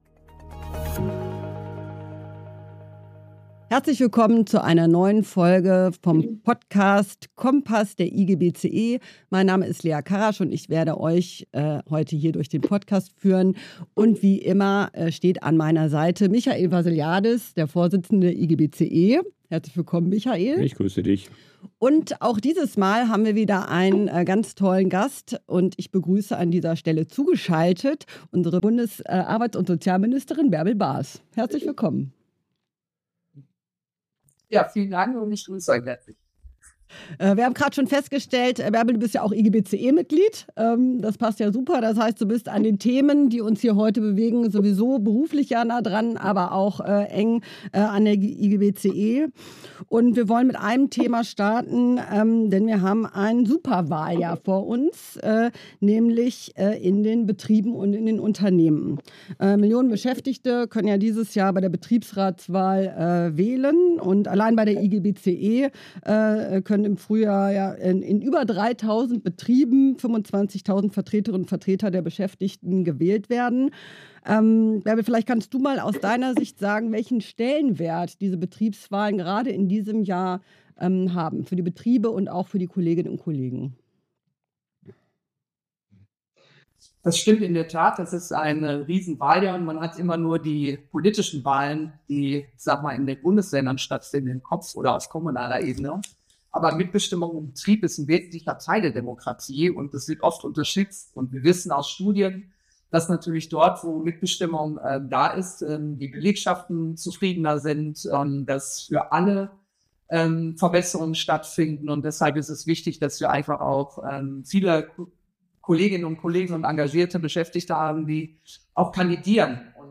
Der Talk zu Mitbestimmung, Arbeitsmarkt und Sozialreformen: Bundesarbeitsministerin Bärbel Bas und der IGBCE-Vorsitzende Michael Vassiliadis diskutieren im neuen „Kompass“ über die Zukunft der Industrie und ihrer Arbeitsplätze. Und machen klar, dass nicht die Beschäftigten das Problem sind.